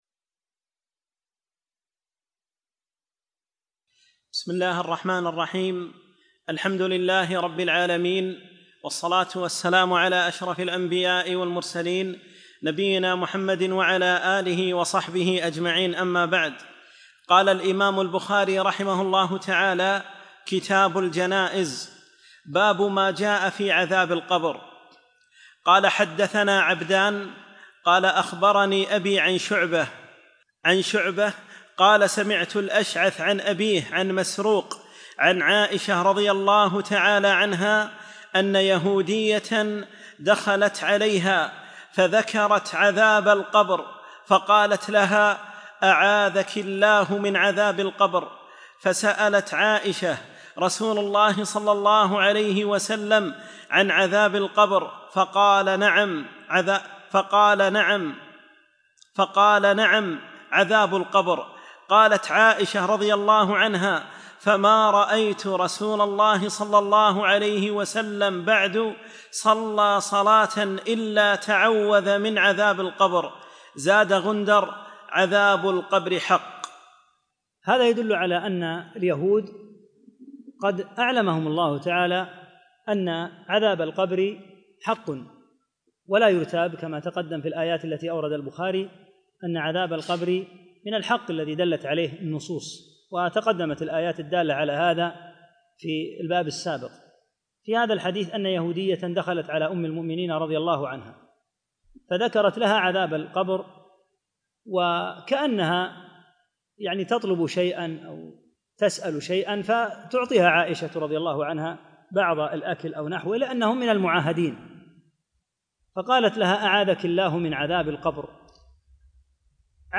11 - الدرس الحادي عشر